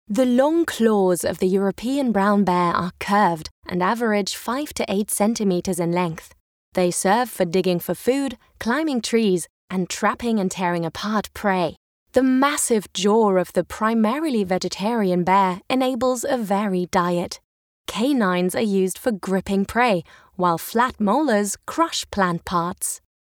Natürlich, Verspielt, Cool, Vielseitig, Warm
Audioguide